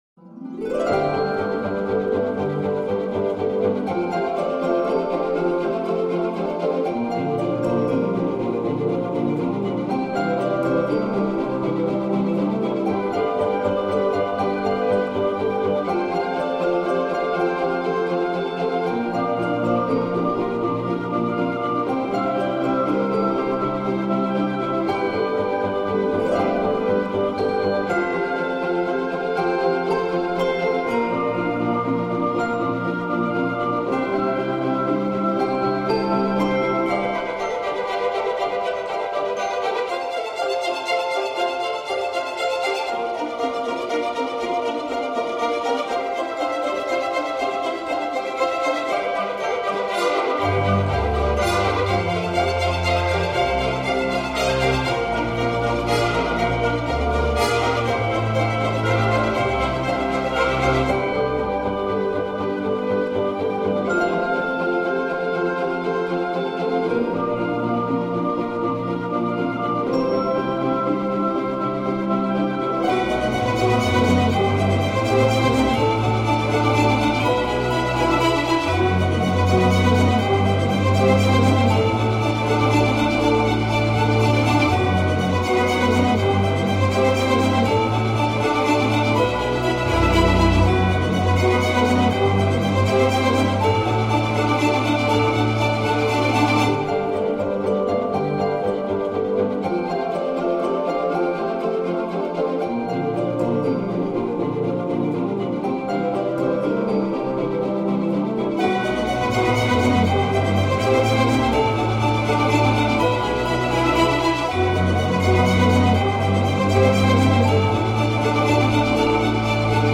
Звучание зачарованных мелодий